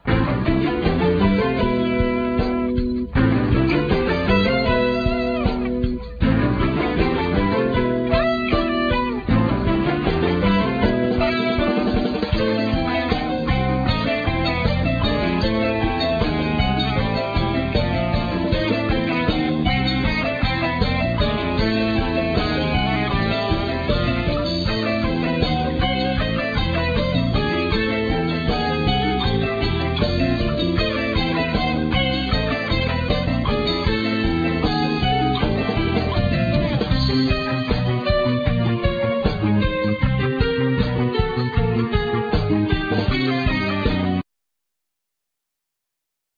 Guitar,Bouzouki,Veena,Voice
Drums,Percussion,Marimba,Voice
Keyboards,Accordion,Melodica,Voice
Bass,Voice